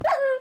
wolf
hurt3.ogg